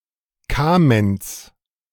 Kamenz (German: [ˈkaːmɛnts]
De-Kamenz.ogg.mp3